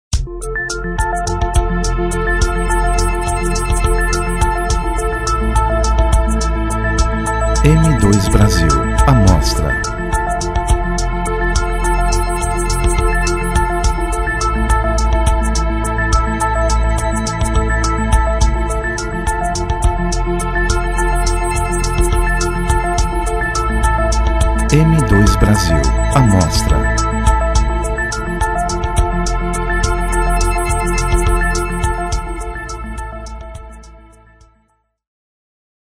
Músicas de Fundo para URA